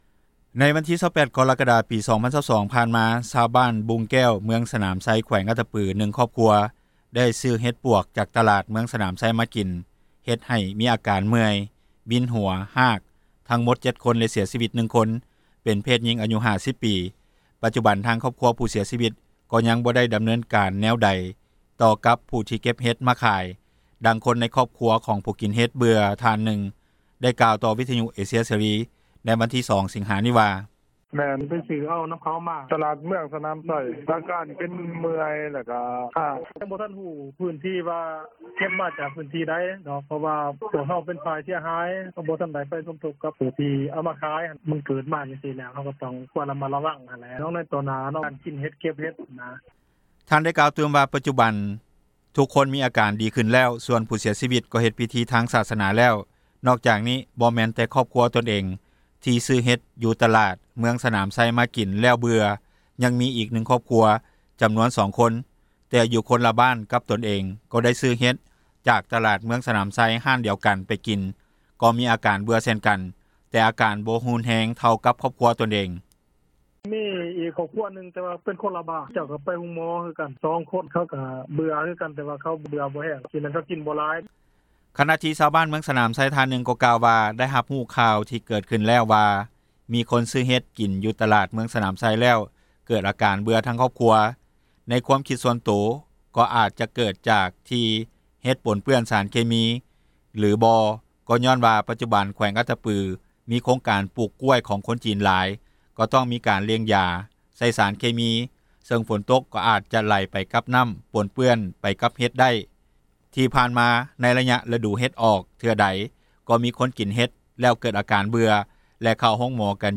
ດັ່ງຄົນໃນຄອບຄົວ ຂອງຜູ້ກິນເຫັດເບື່ອ ທ່ານນຶ່ງໄດ້ກ່າວຕໍ່ວິທຍຸເອເຊັຽເສຣີ ໃນວັນທີ 02 ສິງຫາ ນີ້ວ່າ:
ພ້ອມດຽວກັນນີ້ ນັກວິຊາການດ້ານສາທາຣະນະສຸຂ ໃນແຂວງອັດຕະປືທ່ານນຶ່ງ ກໍໄດ້ໃຫ້ຄວາມເຫັນວ່າທີ່ຜ່ານມາ ໃນໄລຍະລະດູຝົນ ຈະມີຂ່າວຊາວບ້ານກິນເຫັດເບື່ອ ເກີດຂຶ້ນທຸກປີ ແລະບໍ່ແມ່ນແຕ່ແຂວງອັດຕະປື ຍັງມີແຂວງອື່ນໆ ອີກຫຼາຍແຂວງນຳດ້ວຍ.